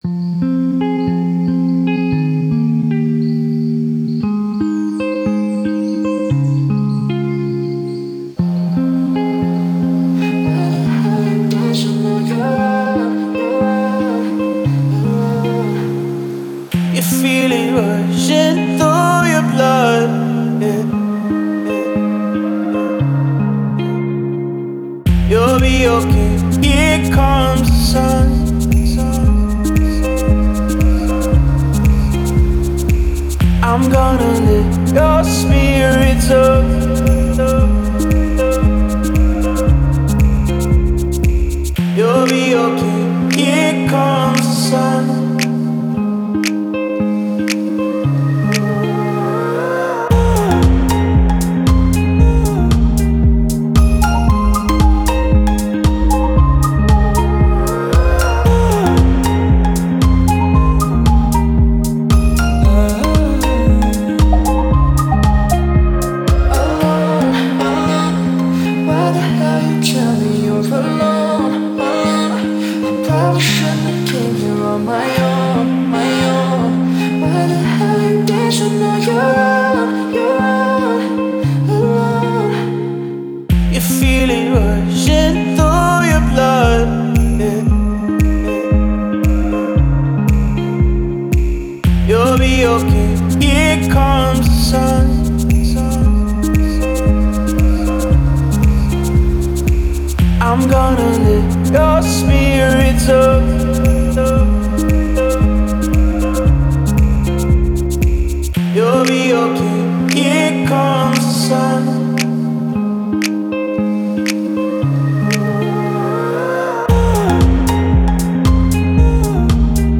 это яркая и мелодичная песня в жанре инди-поп